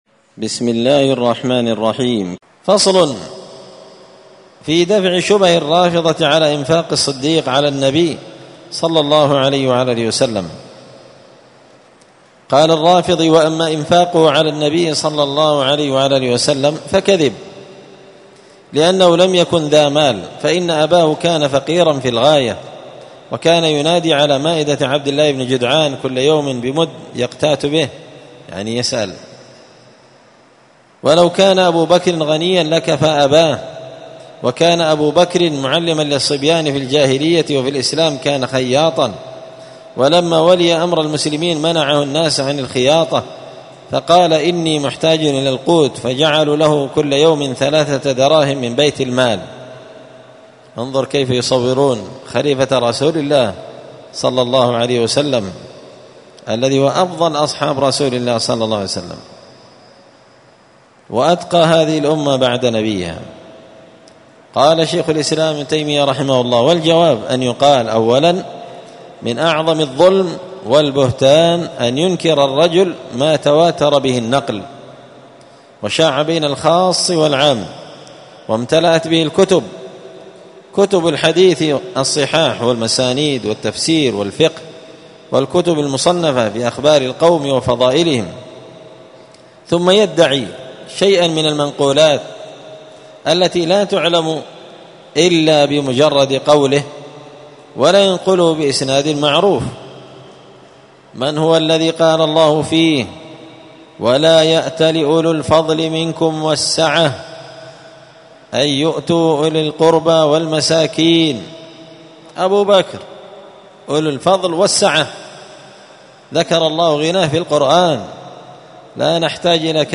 *الدرس الخمسون بعد المائتين (250) فصل في دفع شبه الرافضة على إنفاق الصديق على النبي*
مسجد الفرقان قشن_المهرة_اليمن